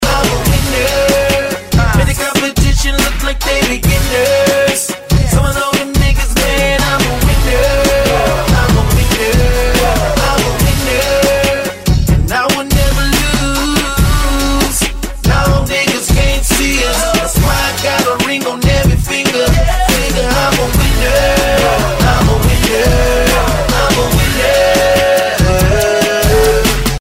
Rap, RnB, Hip-Hop